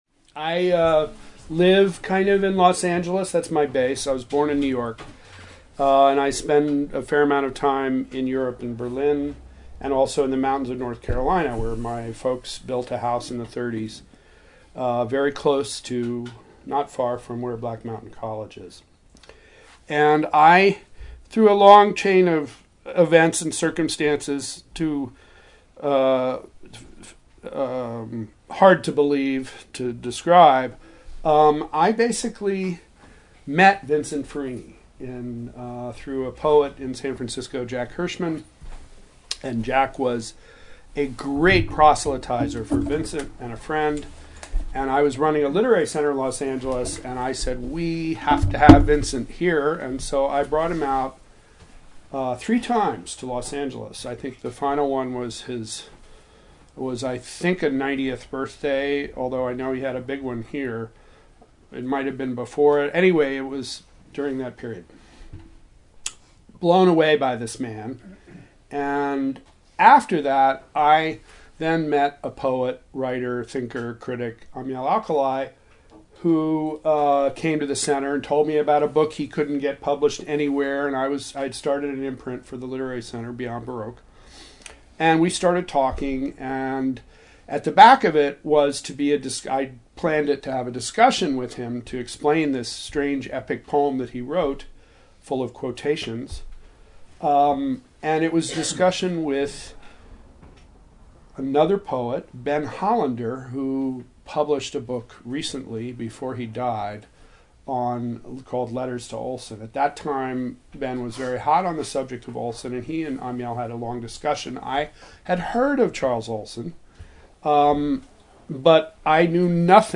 Tonight, we host a discussion